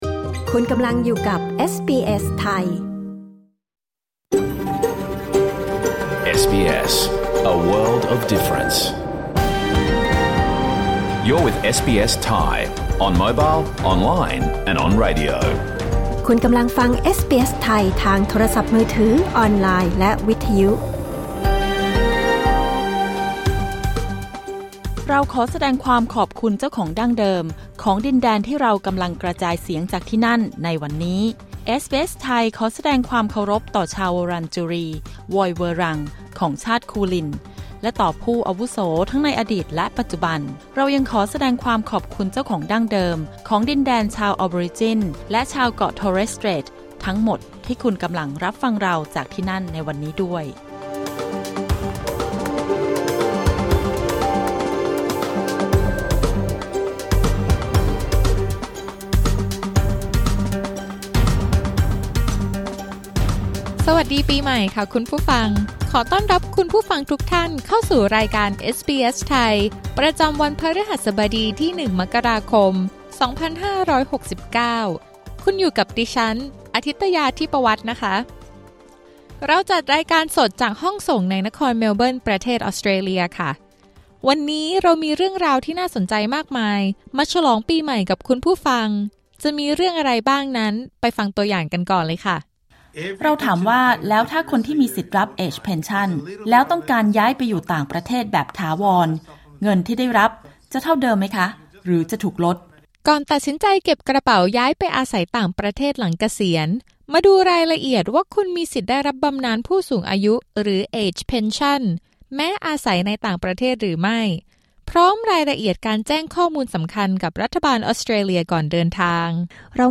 รายการสด 1 มกราคม 2569